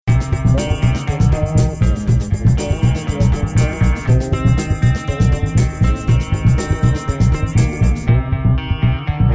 background-music-aac.mp3